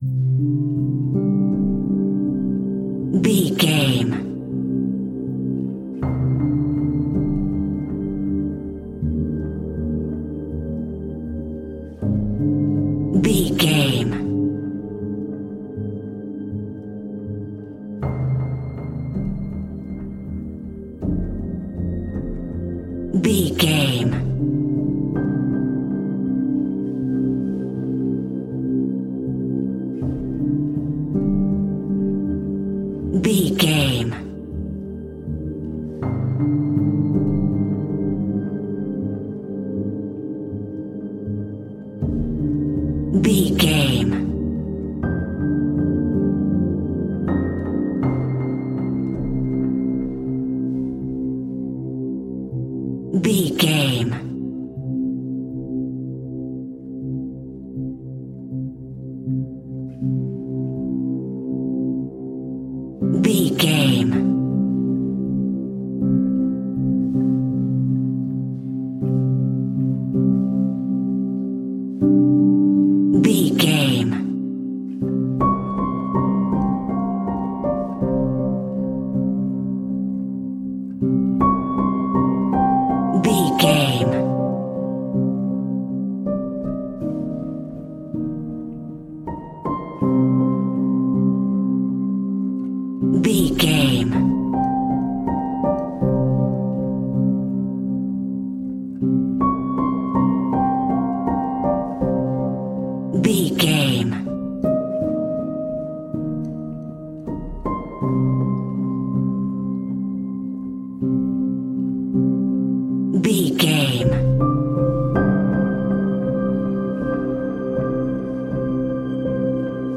Aeolian/Minor
synthesiser